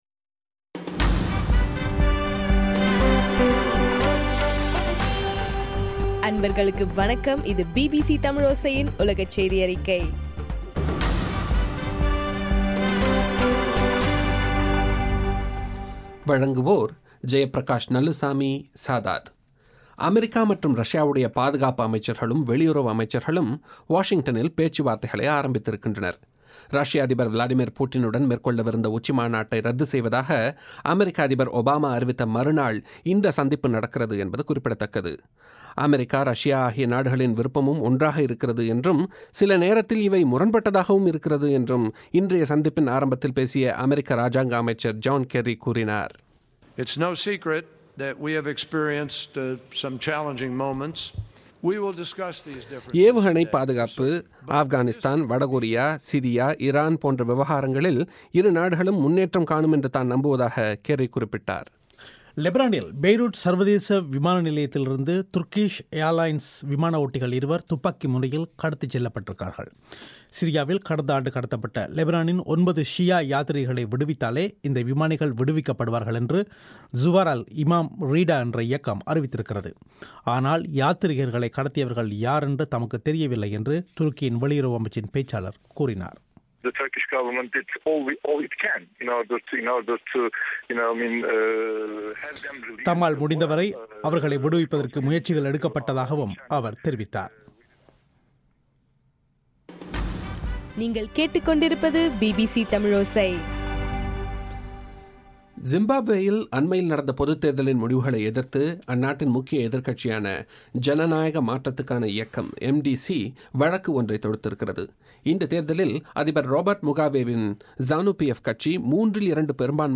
இலங்கையின் சக்தி எஃப்எம் வானொலியில் ஒலிபரப்பான பிபிசி தமிழோசையின் உலகச் செய்தியறிக்கை